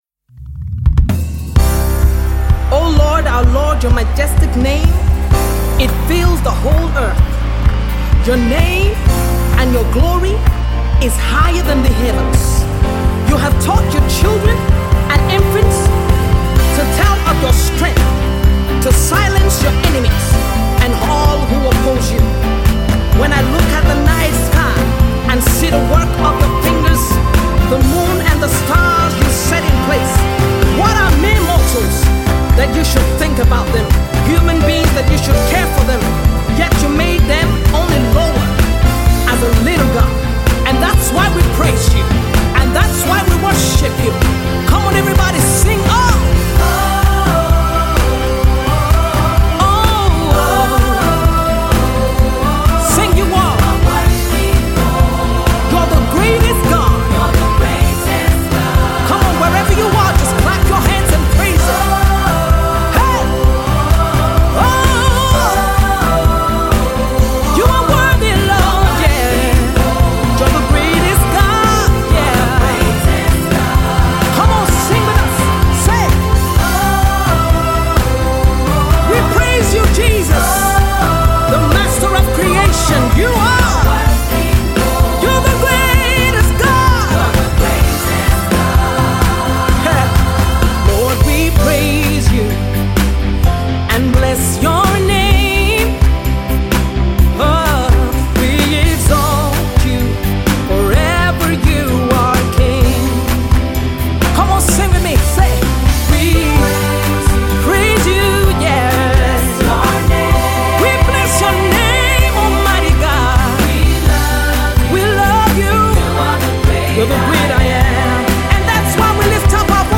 Scotland-based singer-songwriter